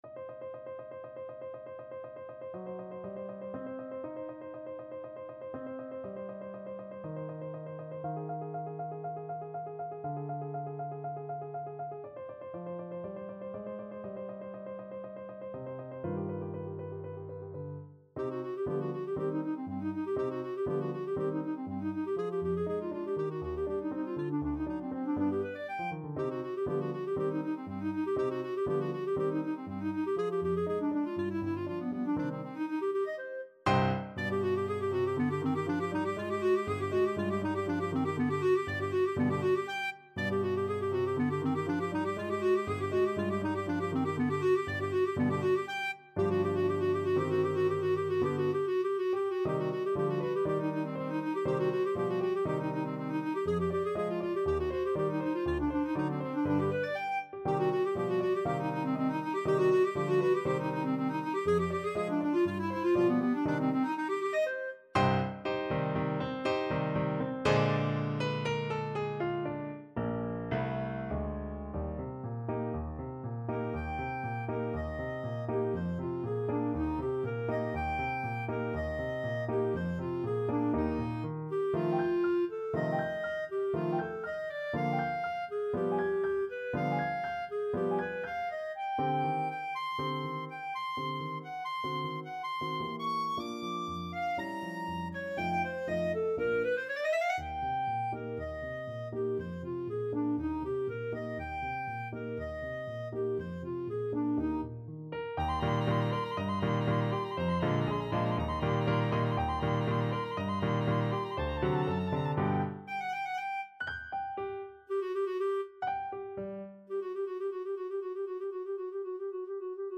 Clarinet
C minor (Sounding Pitch) D minor (Clarinet in Bb) (View more C minor Music for Clarinet )
2/4 (View more 2/4 Music)
= 120 Allegro molto vivace (View more music marked Allegro)
Classical (View more Classical Clarinet Music)